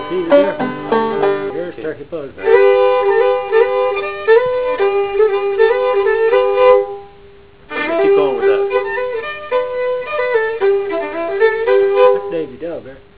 "Here's 'Turkey Buzzard," he said, and he played the low part.